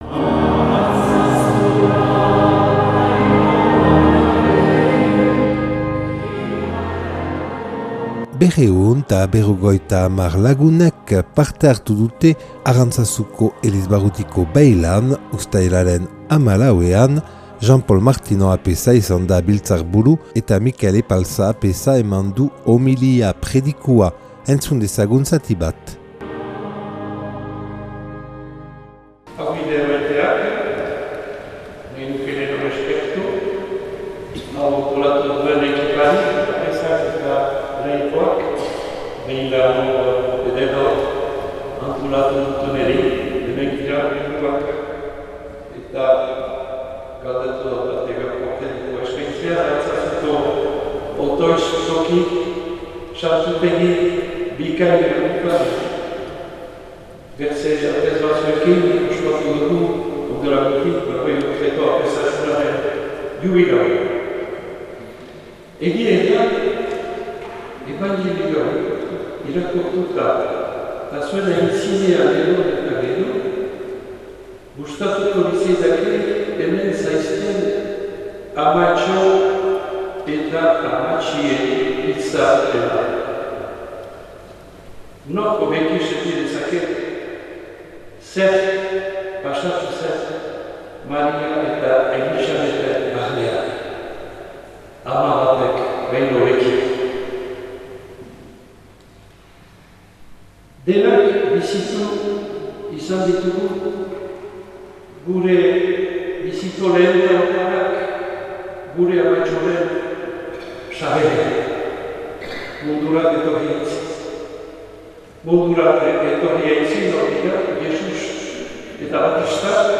250 lagunek parte hartu dute Arantzazuko elizbarrutiko beilan, uztailaren 14an, ostiralarekin.